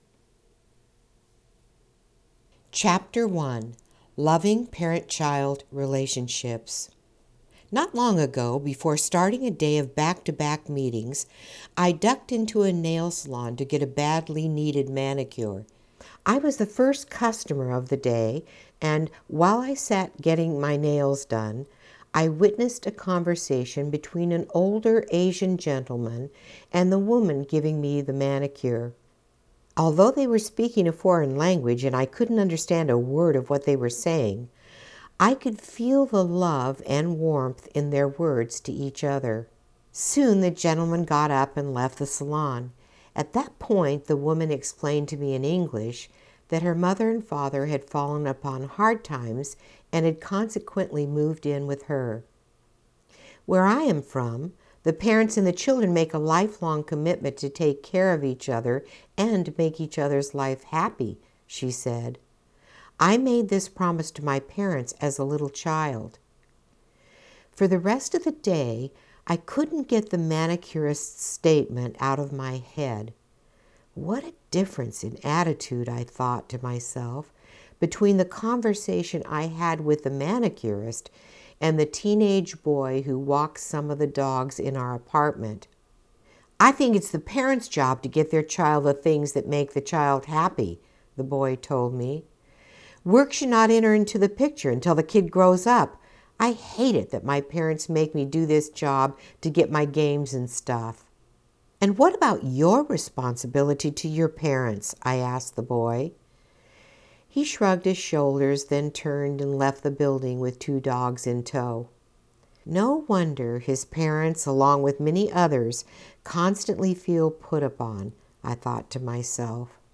AUDIO BOOK ABOUT PARENT/CHILD RELATIONSHIPS FOR PARENTS